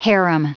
Prononciation du mot harem en anglais (fichier audio)